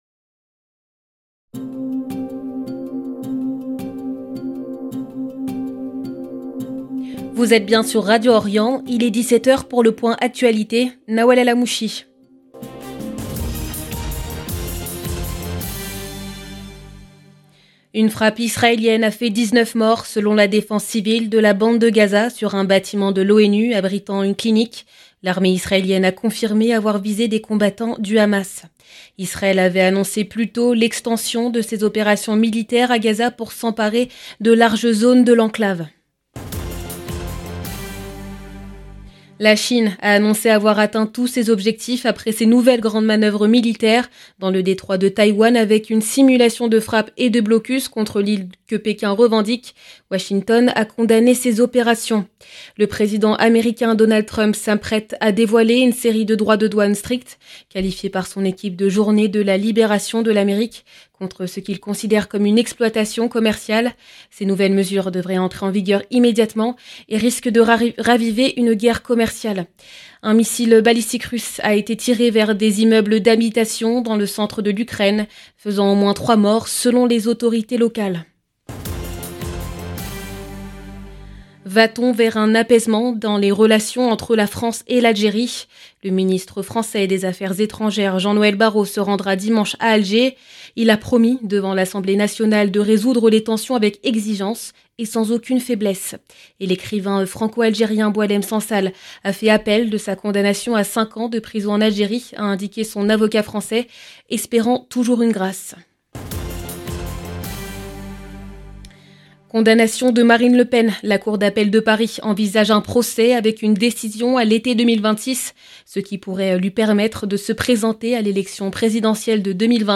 LE JOURNAL EN LANGUE FRANCAISE DU SOIR 02/04/2025